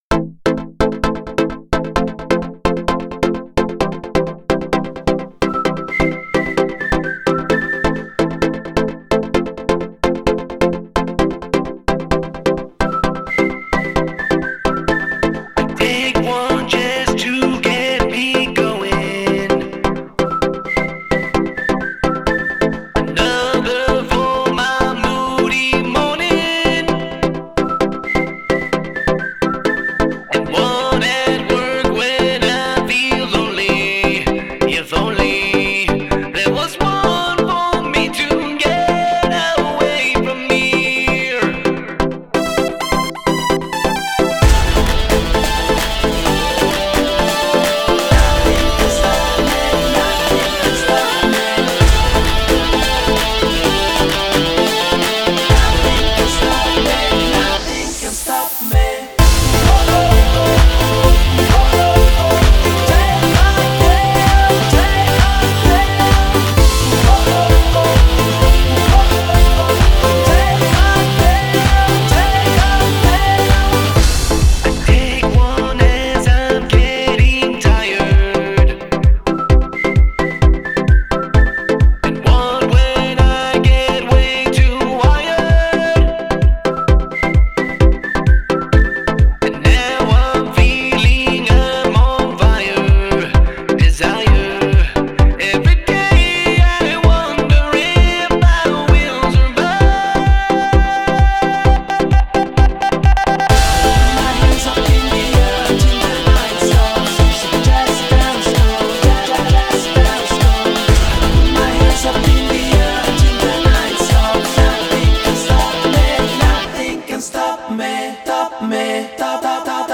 sobre el tema de dance pop